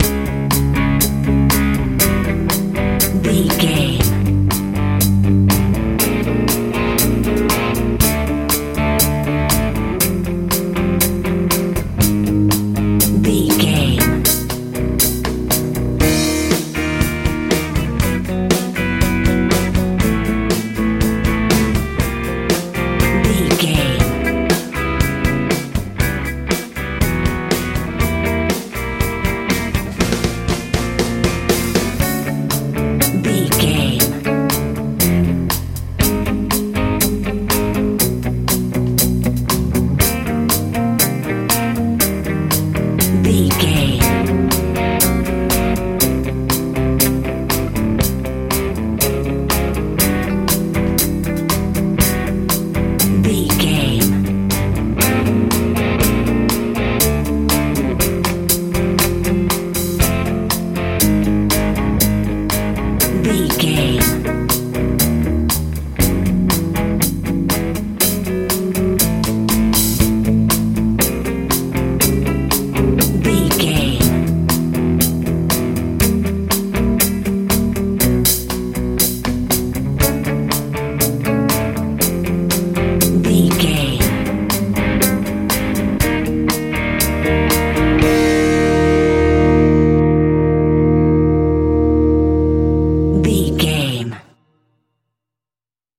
Med rock music
Ionian/Major
aggressive
determined
drums
bass guitar
electric guitar
80s
positive
lively
energetic
driving